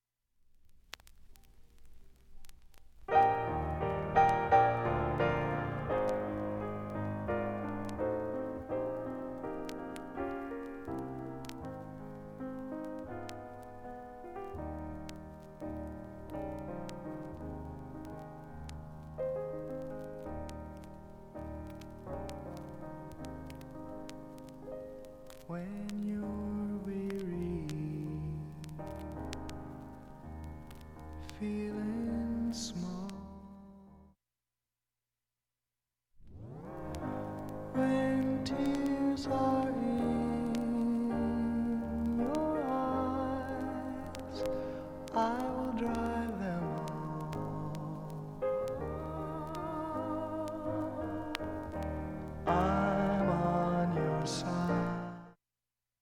盤面もきれいです音質良好全曲試聴済み。
A-1始めに２ミリスレで５５秒の間に周回プツ出ますが
かすかで聴き取り出来るか不安なレベルです。